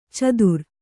♪ cadur